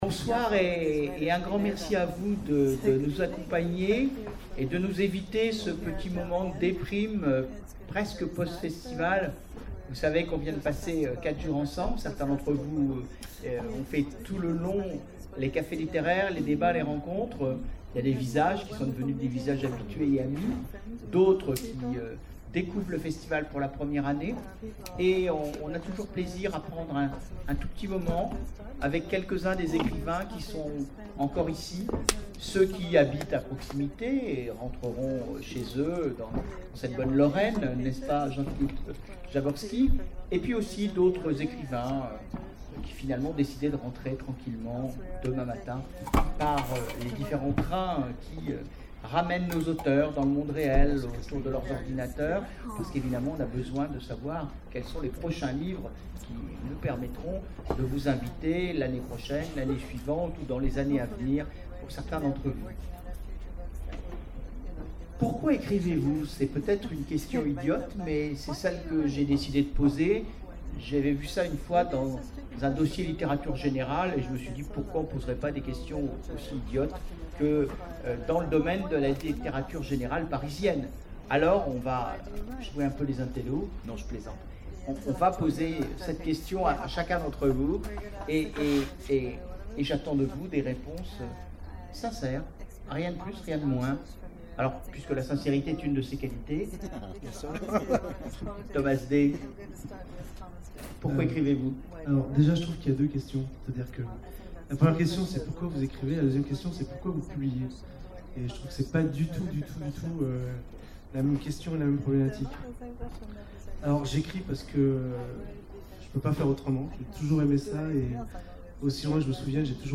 Imaginales 2012 : Conférence Un dernier pour la route !